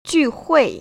[jùhuì] 쥐후이